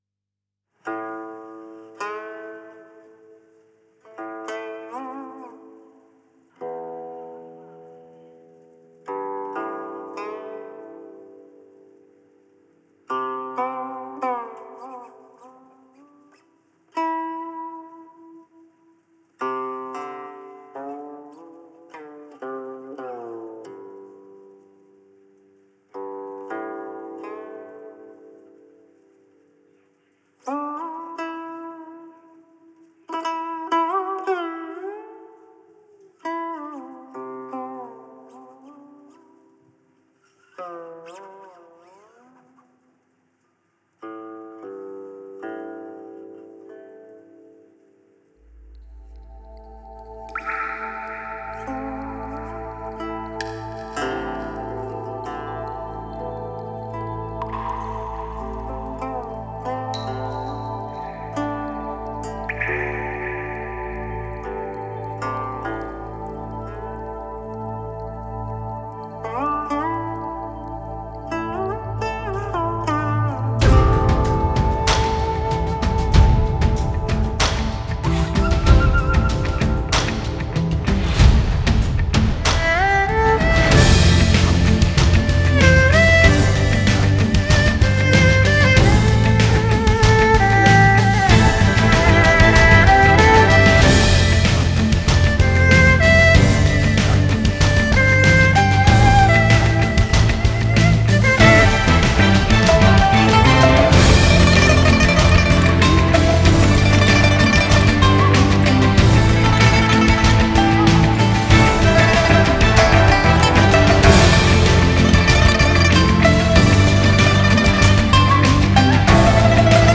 Ps：在线试听为压缩音质节选，体验无损音质请下载完整版
吉 他
古 琴
笛 箫
琵 琶
二 胡